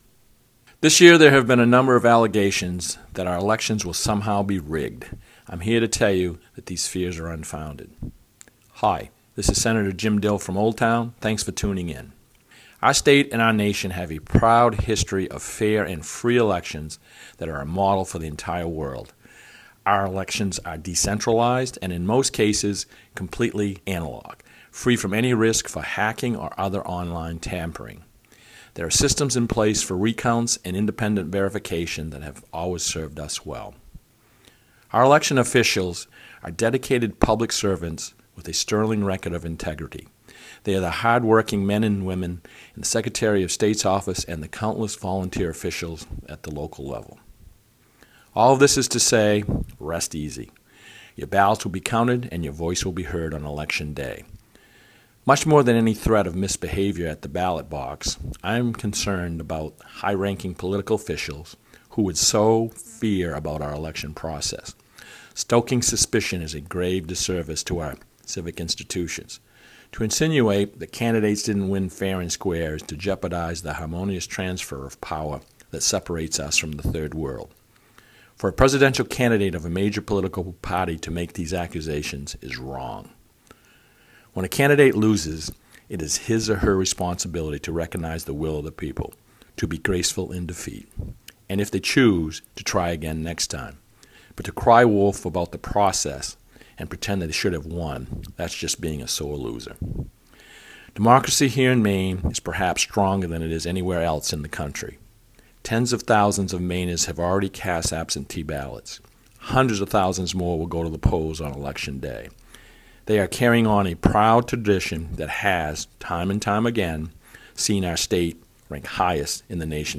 Weekly Radio Address: Sen. Dill says fears of ‘rigged’ election are completely unfounded.